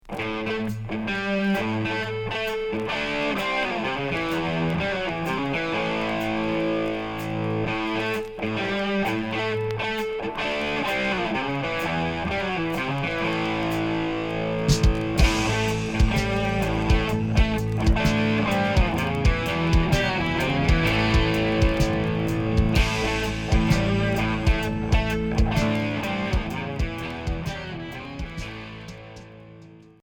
Hard rock Premier 45t retour à l'accueil